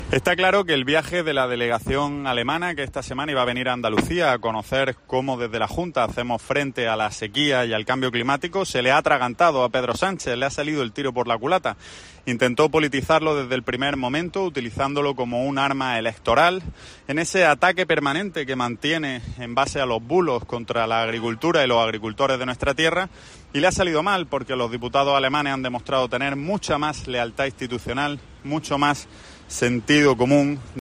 Ramón Fernández Pacheco, portavoz del Gobierno andaluz